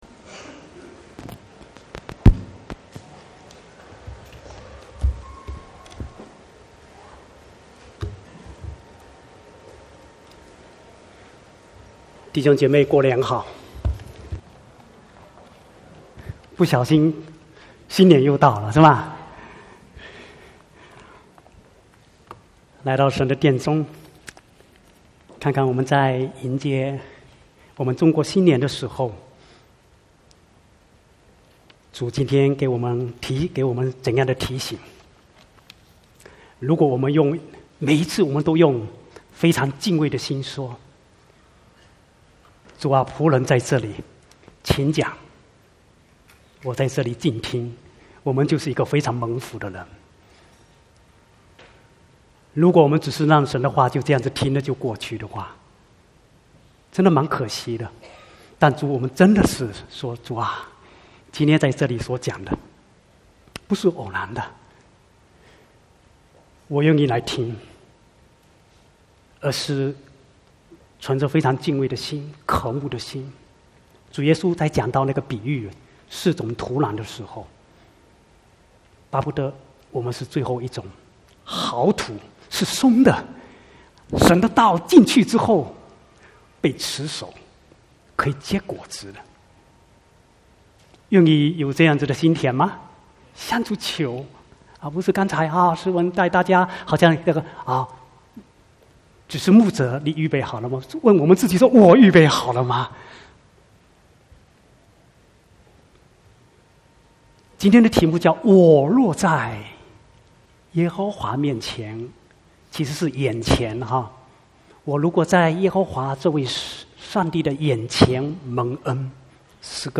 19/1/2020 國語堂講道